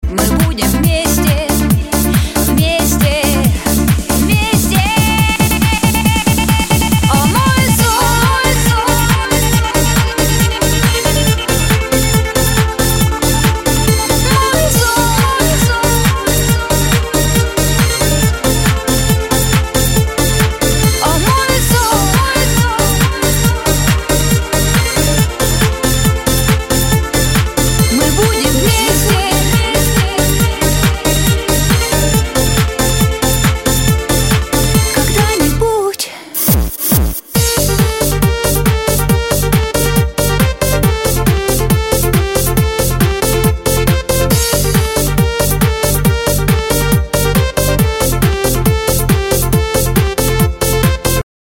• Качество: 128, Stereo
club
(клубная музыка 80-х)